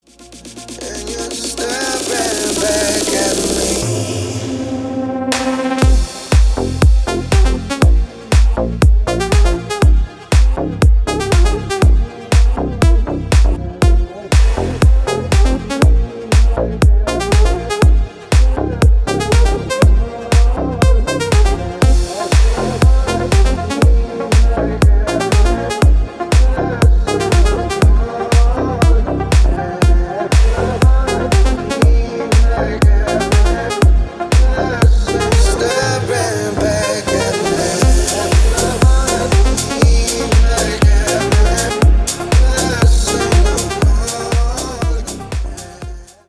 • Качество: 320, Stereo
deep house
красивый мужской голос
Electronic
future house
басы